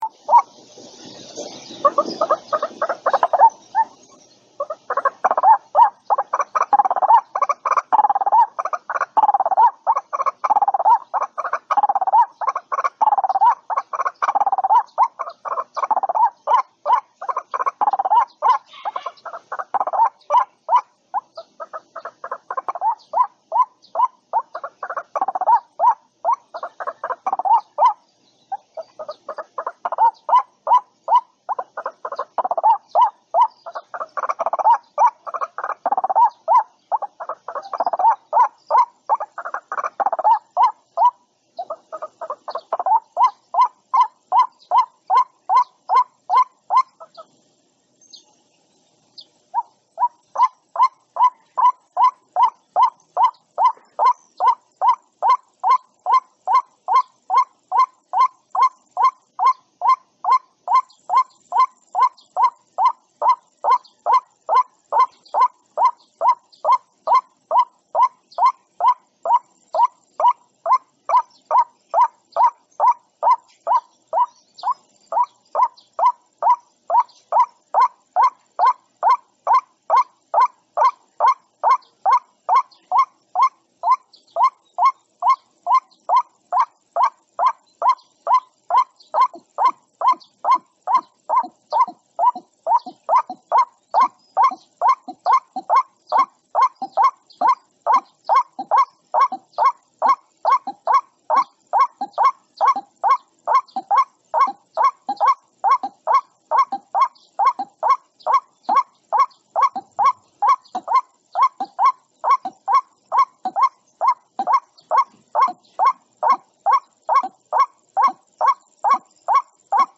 เสียงนกกวักร้องหาคู่ เสียงชัด 100%
เสียงลูกนกกวัก เสียงดี เสียงชัด100% mp3 เสียงนกกวักตัวเมีย
หมวดหมู่: เสียงนก